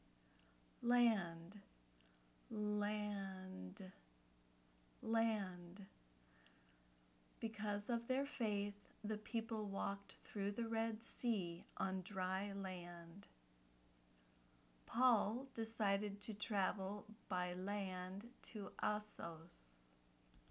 /lænd/ (noun)